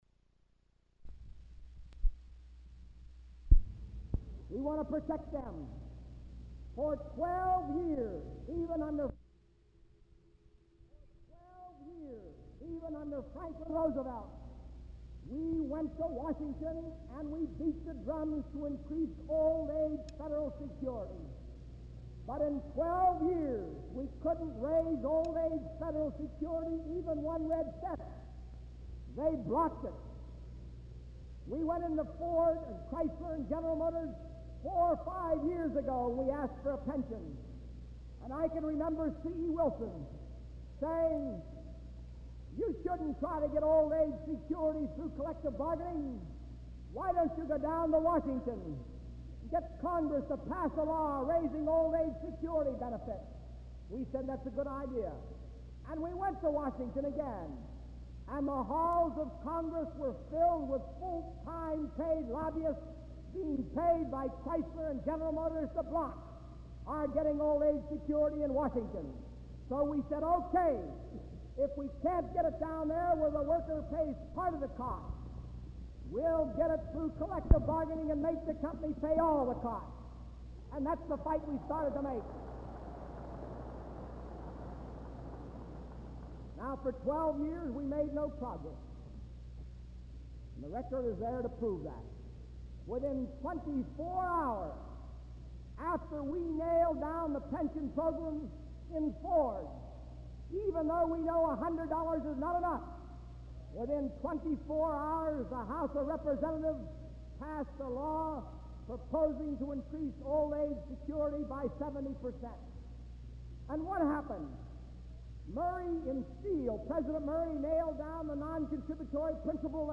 Walter P. Reuther Digital Archive · Walter P. Reuther - Speech at State Fairgrounds, Detroit, MI, Part Two · Omeka S Multi-Repository